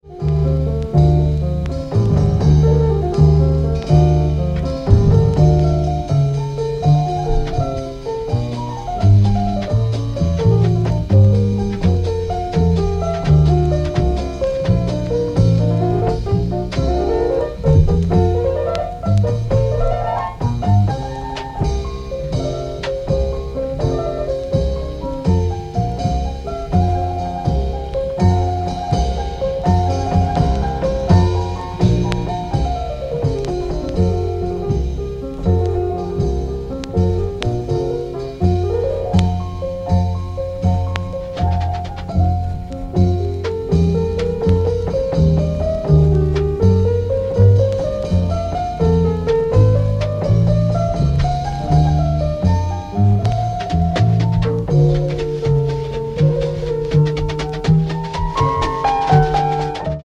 Library session